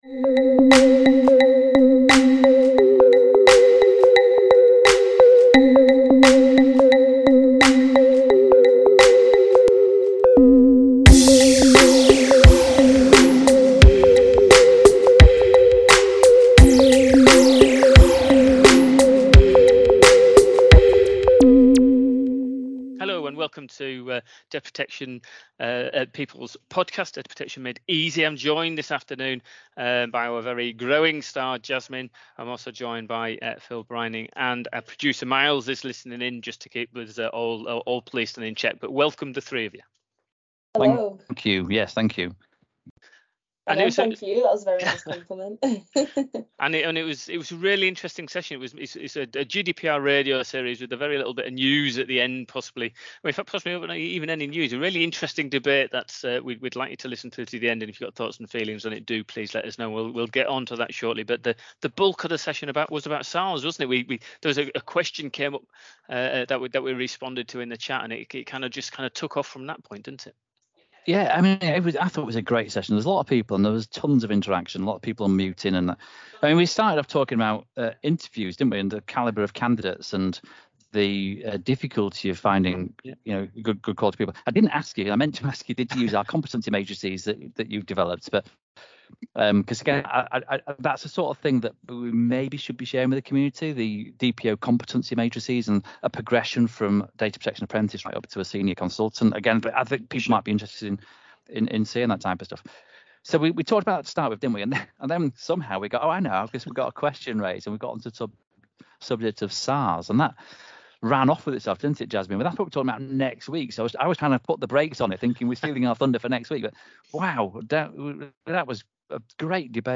During this episode we cover a range of different topics including the latest data protection trends and top tips from our data protection experts. You will also notice this episode has a lot of listener engagement, we had over 100 likeminded individuals joining us live, if you would like to tune in and get involved on future episodes of the podcast, click here.